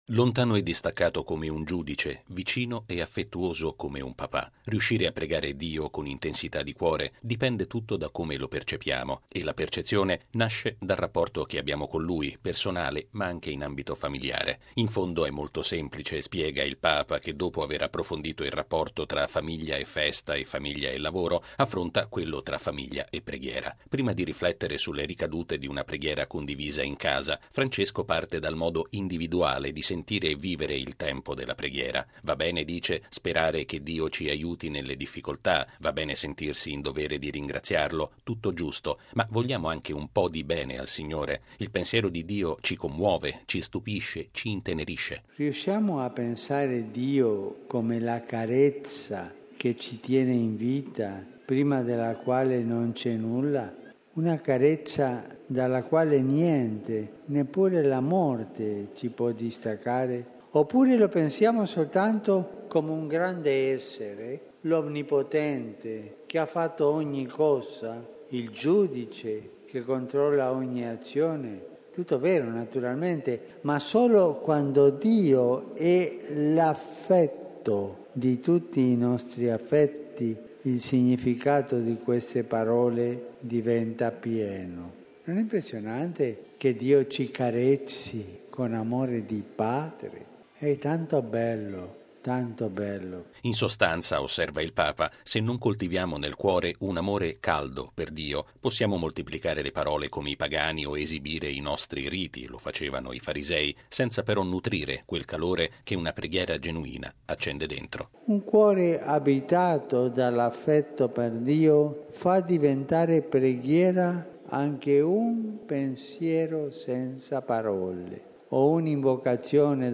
Lo ha spiegato Papa Francesco, dedicando al rapporto famiglia e preghiera la catechesi dell’udienza generale presieduta in Piazza San Pietro, la 100.ma dall’inizio del Pontificato.